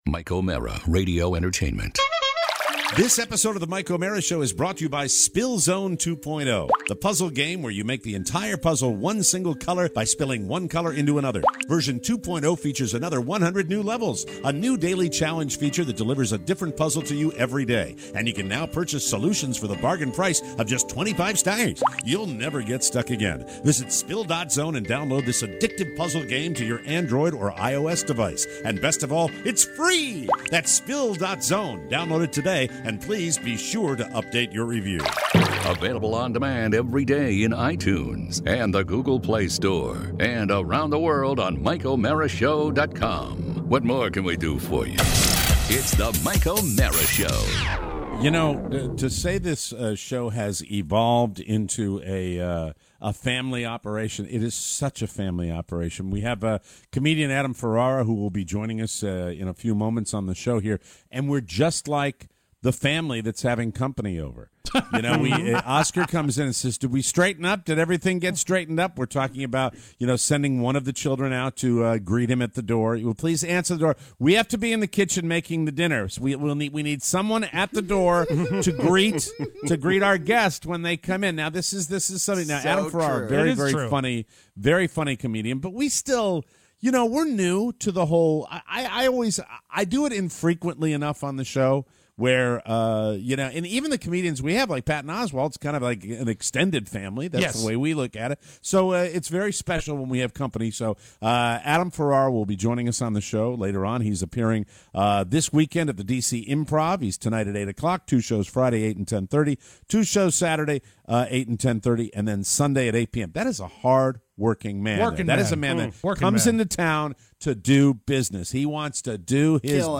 Today, comedian Adam Ferrara in studio.